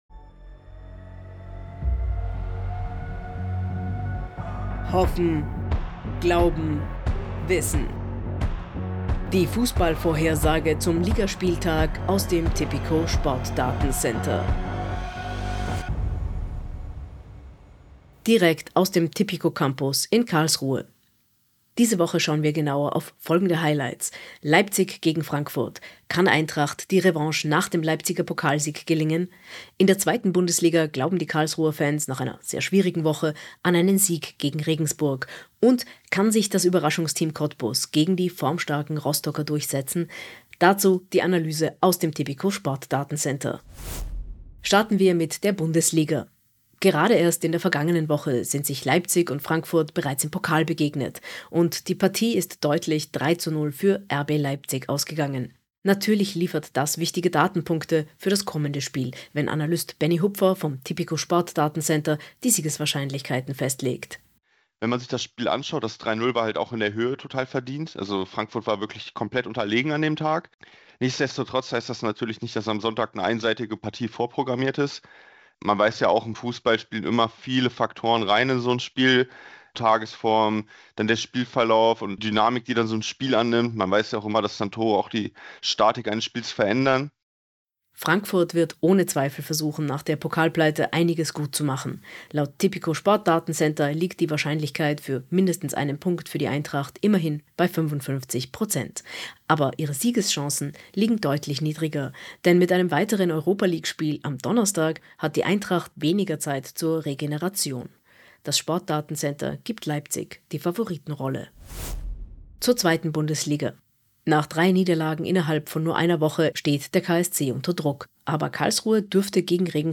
Die Pressemeldung im Audio-Newscast: